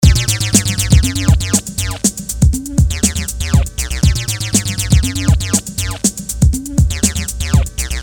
描述：用music maker 15鼓和低音合成器制作的鼓和低音循环。
Tag: 120 bpm Techno Loops Groove Loops 1.36 MB wav Key : Unknown